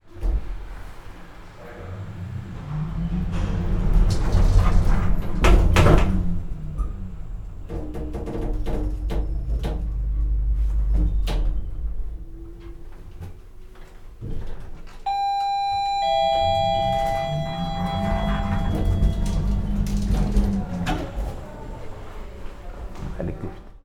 Very old lift
beep bell crackling ding door doors elevator hospital sound effect free sound royalty free Sound Effects